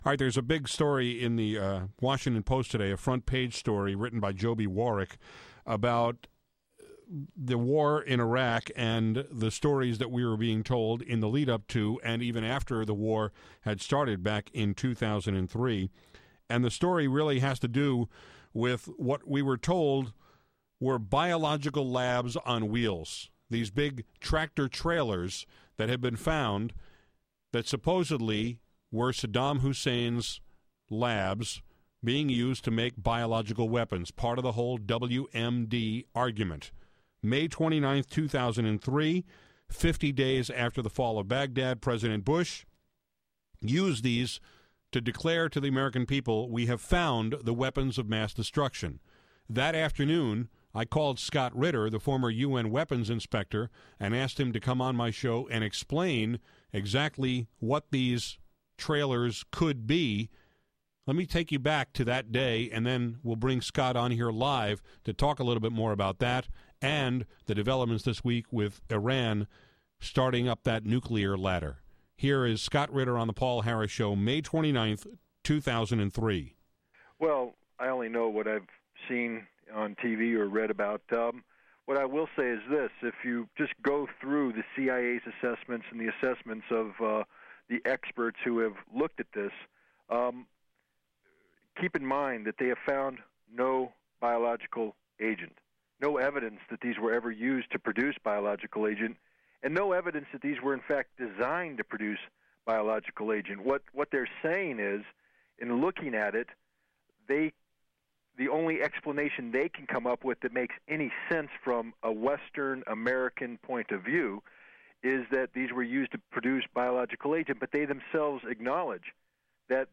I invited former UN weapons inspector Scott Ritter back onto my show to talk about developments in the Iran nuclear story, with their President and Deputy Nuclear Chief crowing about their ability to produced low-grade enriched uranium.
I dug up that audio and played it as part of today’s conversation, which you can listen to here, then click here to subscribe to these podcasts via iTunes!